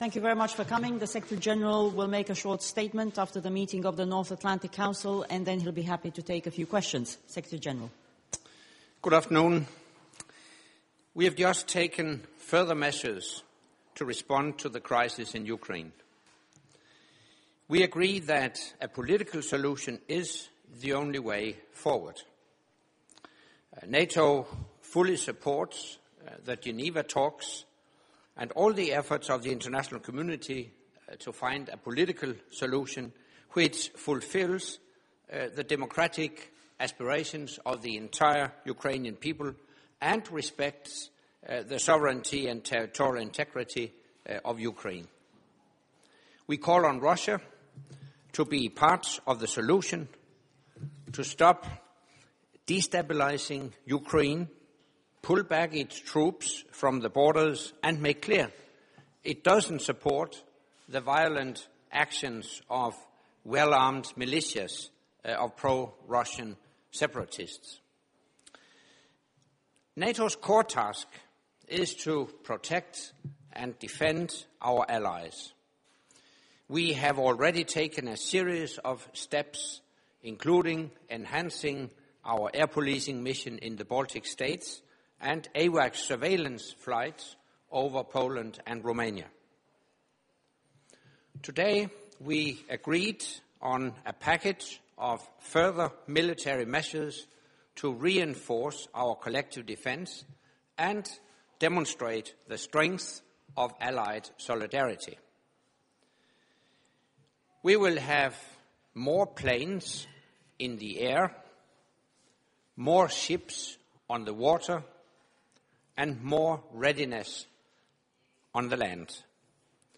Doorstep statement by NATO Secretary General Anders Fogh Rasmussen following the meeting of the North Atlantic Council